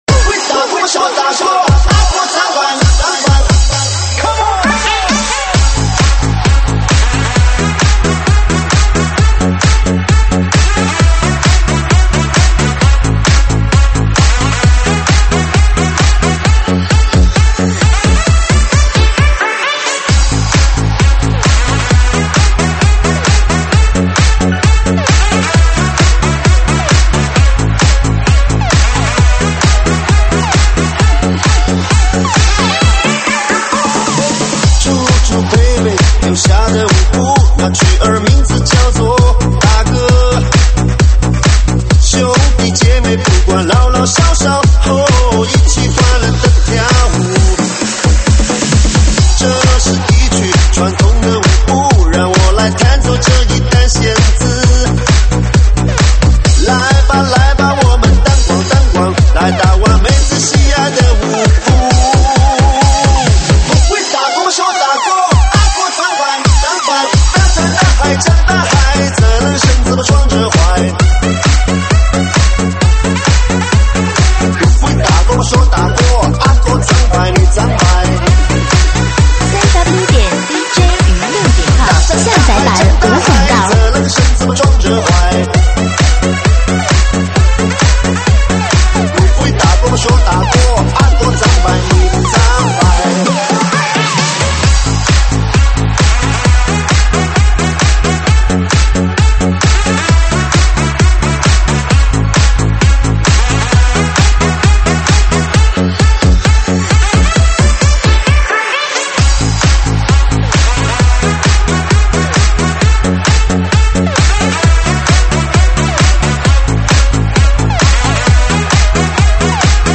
中文慢摇